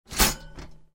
Звуки тостера
Звук готового тоста в механизме тостера